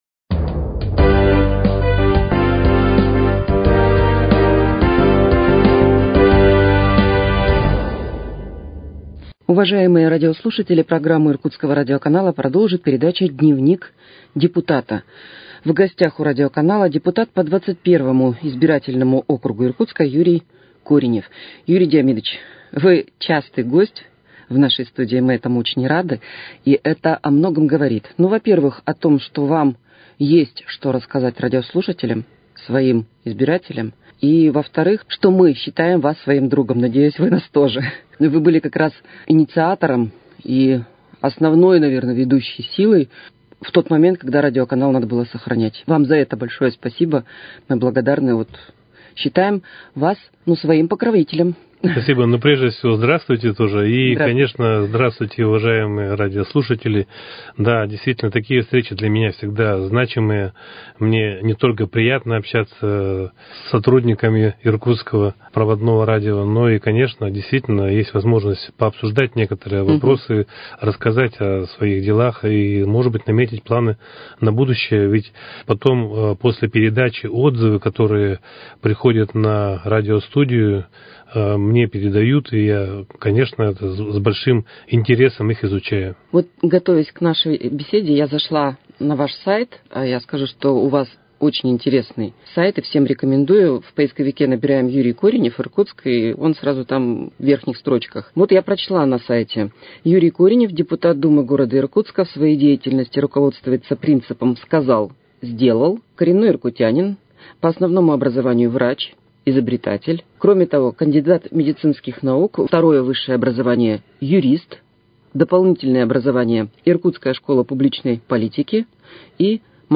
беседует с депутатом Думы г.Иркутска по округу № 21 Юрием Кореневым.